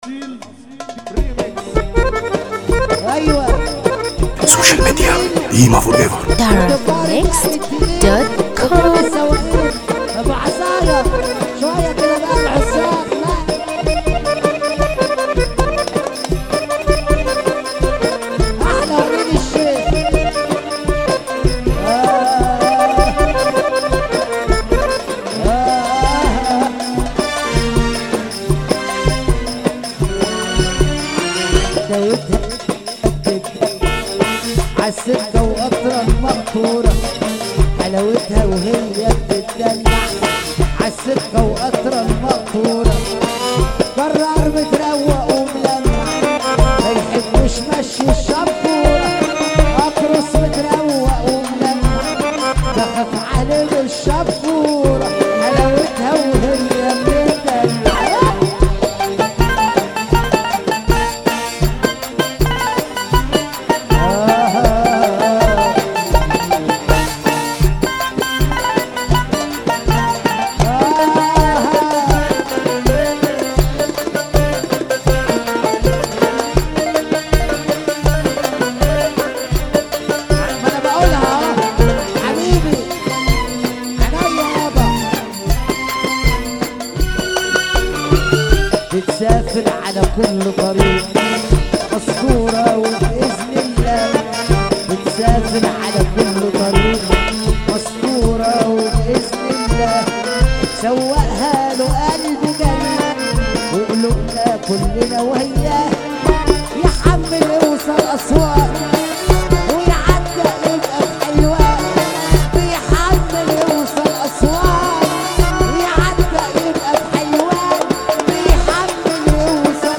موال
حزينة جدا